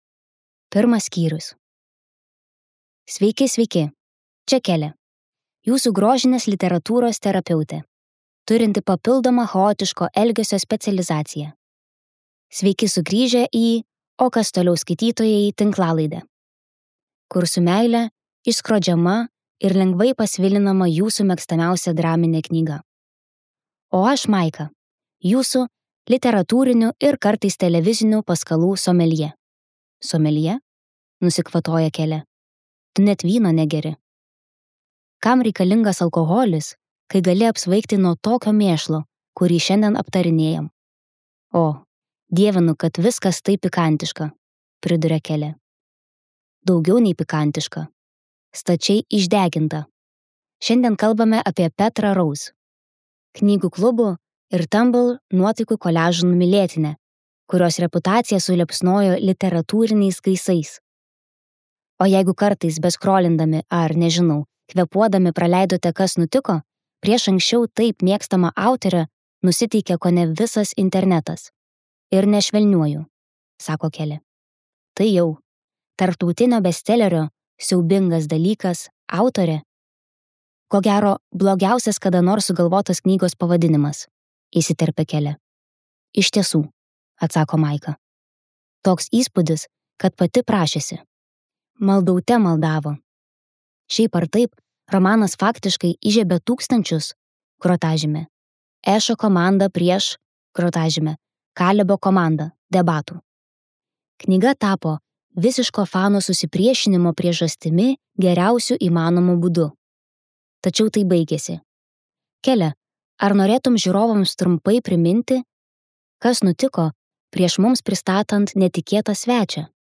Naujoji rašytojos Colleen Hoover audioknyga „Sužeista moteris“. Joje trilerio žanras pinasi su romantika ir kuria jaudinančią skaitymo intrigą ir malonumą, kurią patirs autorės kūrybos gerbėjai.